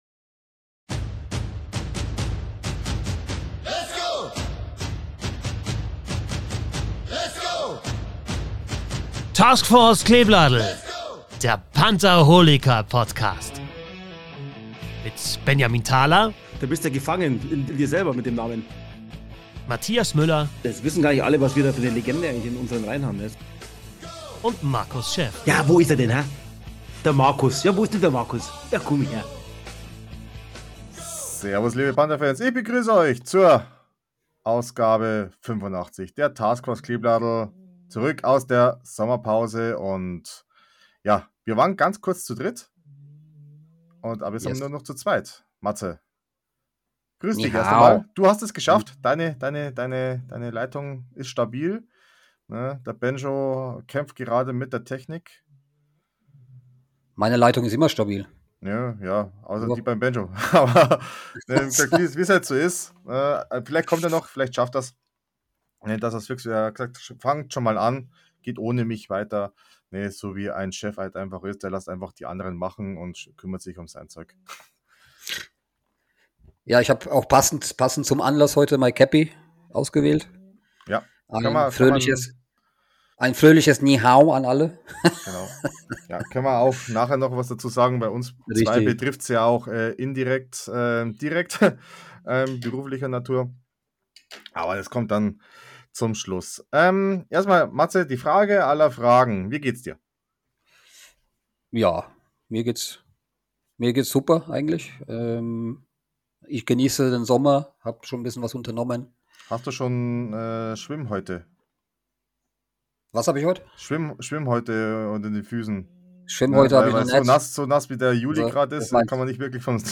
Der Eishockeystammtisch von drei leid geprüften Pantherfans die schon fast alles mitgemacht haben. Aktuelle Themen, nostalgische Geschichten.